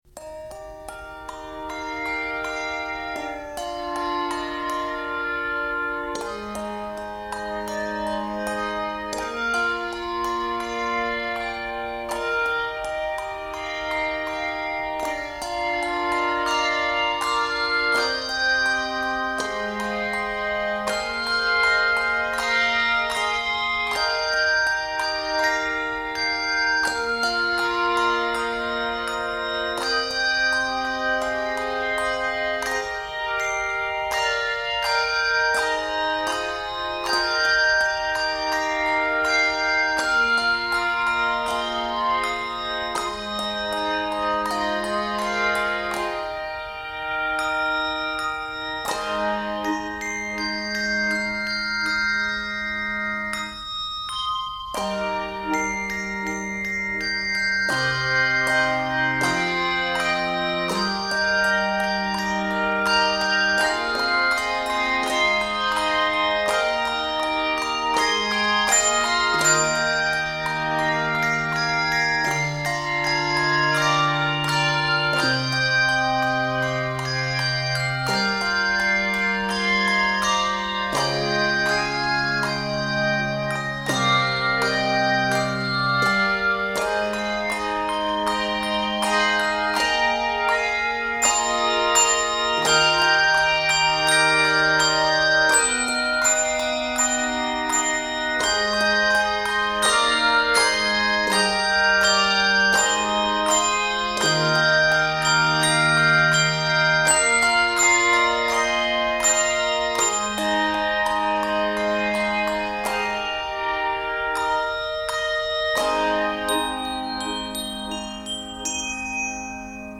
before returning to the relaxed main theme
Key of Eb Major.